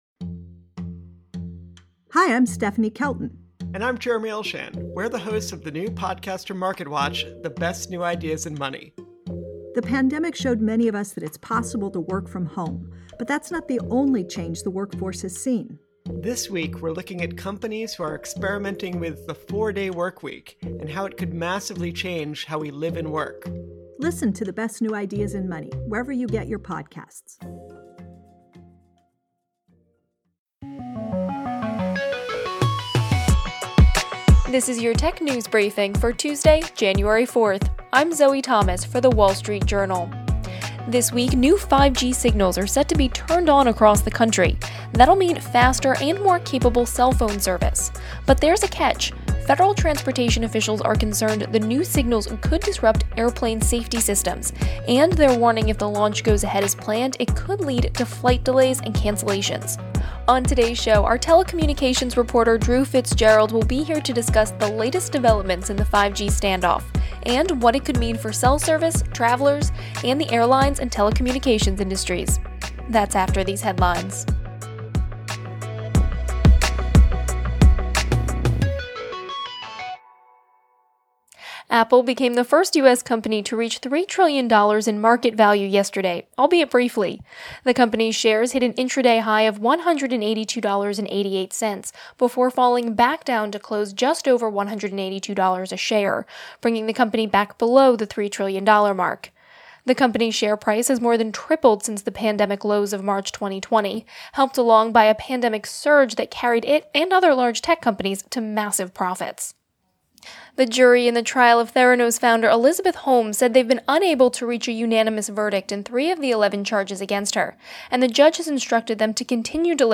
Tech News Briefing / 5G Showdown: Wireless Carriers Rebuff Air Regulators